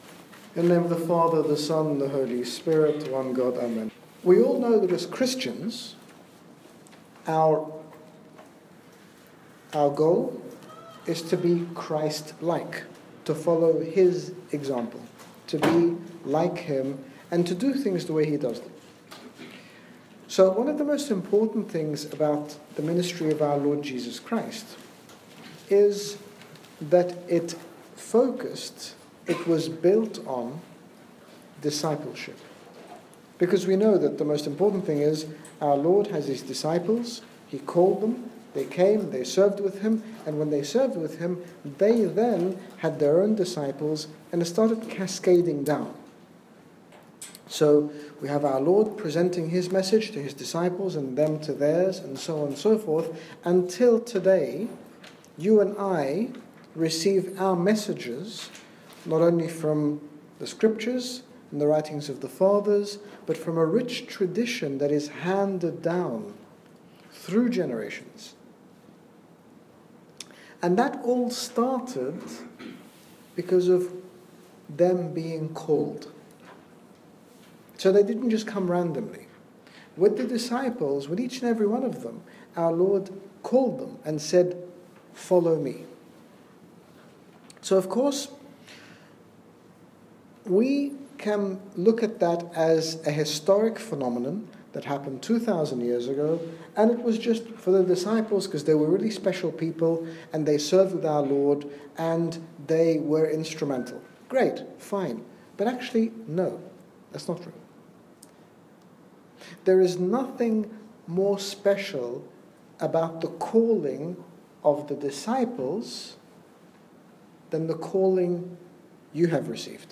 His Grace Bishop Angaelos, General Bishop of the Coptic Orthodox Church in the United Kingdom, speaks to us about being called as ministers / servants of God, living out our lives faithfully and responsibly not only for our benefit but the benefit of everyone we encounter. Download Audio Read more about Called as ministers - HG Bishop Angaelos - Talk 1 of 3 - Being Called Series